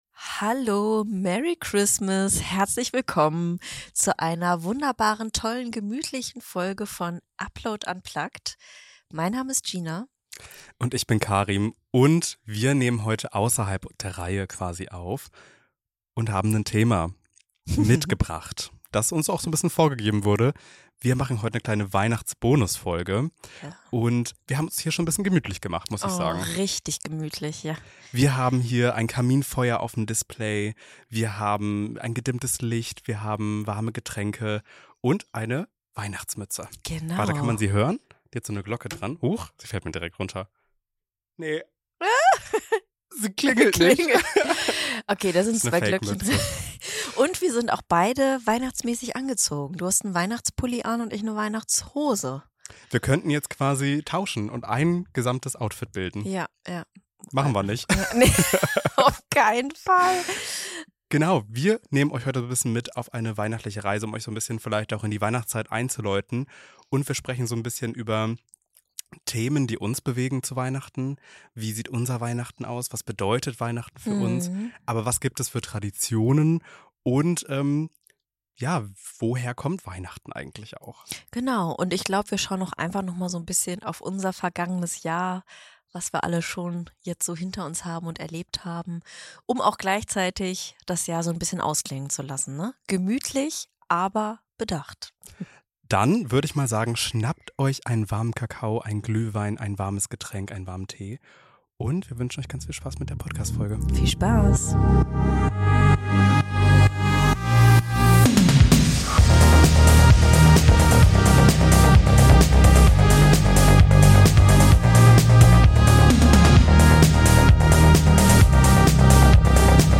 Es geht um Traditionen, Veränderungen, die Perspektive junger Menschen, den Ursprung von Weihnachten und darum, wie jede*r diese besondere Zeit ganz individuell gestalten kann. Locker, warm, nachdenklich, mit einem kleinen Spiel, persönlichen Wünschen und einer Erinnerung daran, Weihnachten so zu feiern, wie es sich für dich richtig anfühlt.